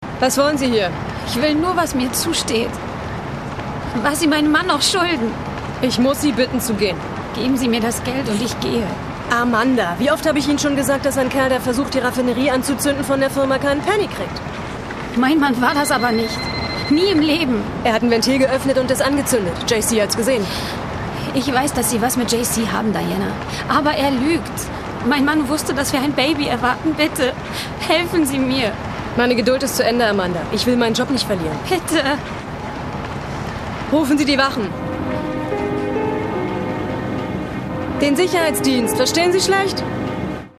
Sprechprobe: Werbung (Muttersprache):
german female voice over artist